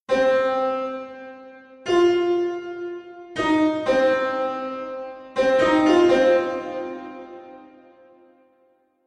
First take of recording a rhythm
spy3_Rhythm_Take_1.mp3